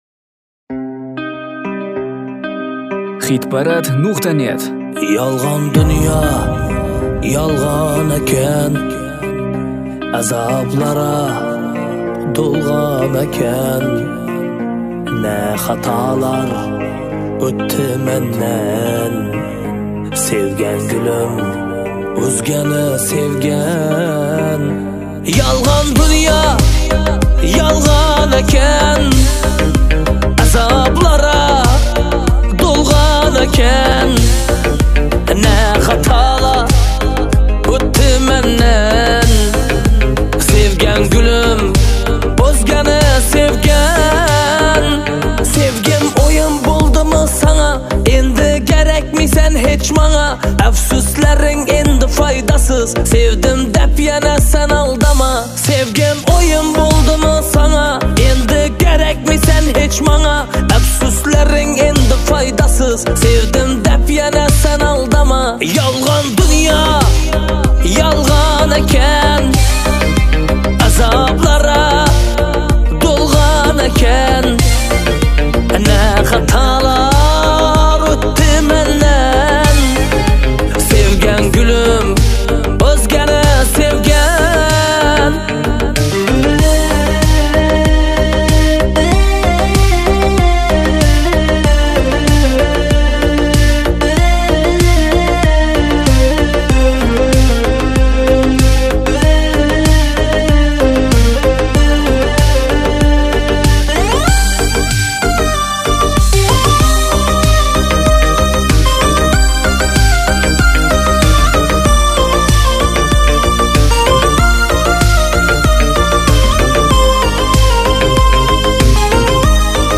в жанре узбекской поп-музыки